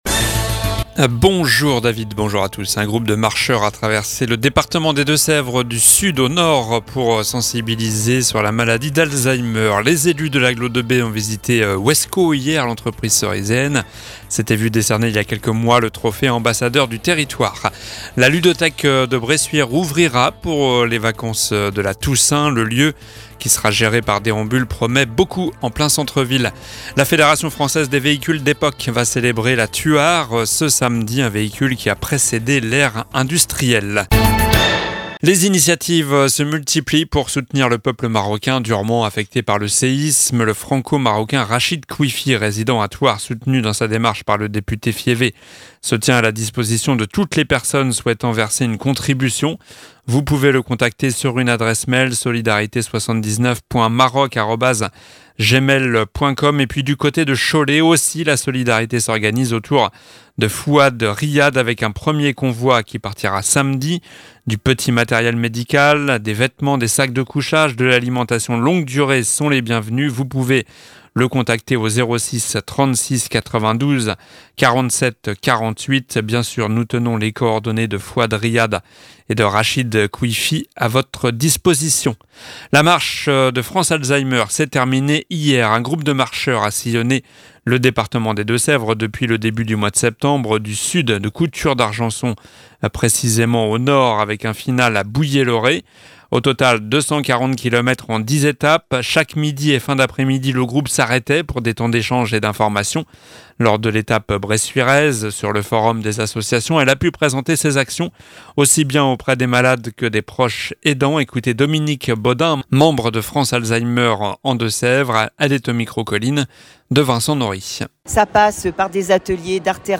Journal du mardi 12 septembre (midi)